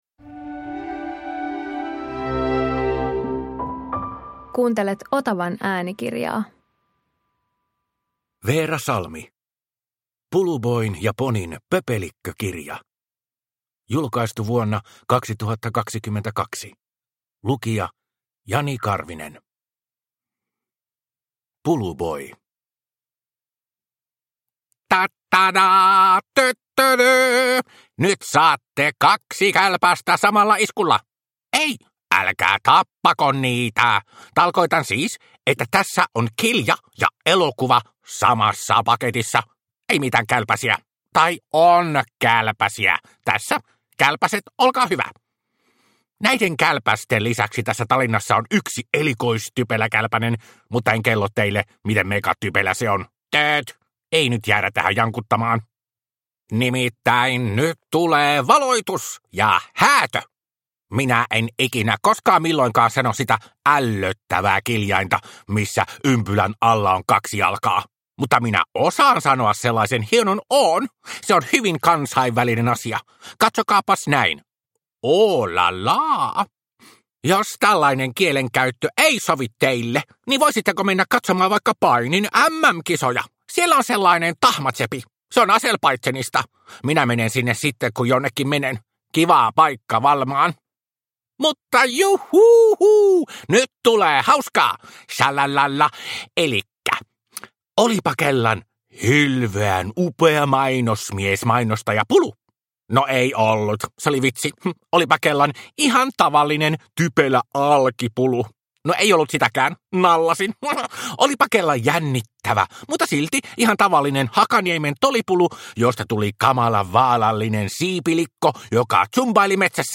Puluboin ja Ponin pöpelikkökirja – Ljudbok – Laddas ner